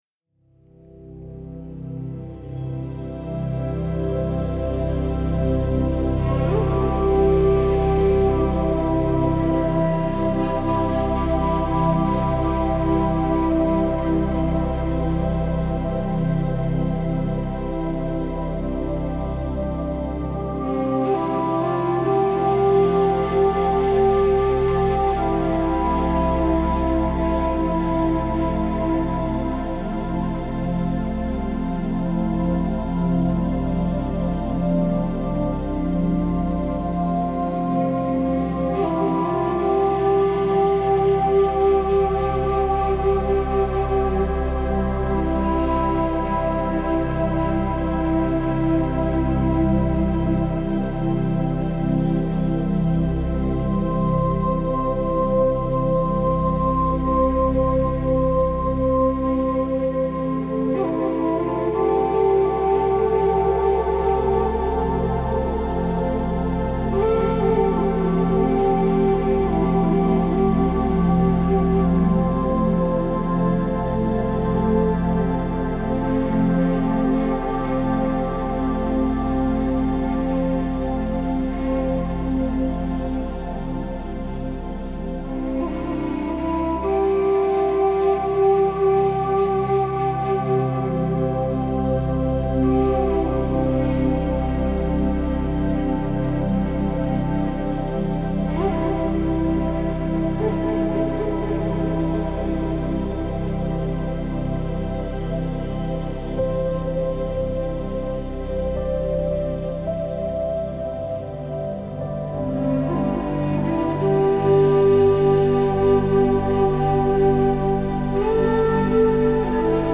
بومی و محلی , مدیتیشن , موسیقی بی کلام
موسیقی بی کلام سرخپوستی موسیقی بی کلام فلوت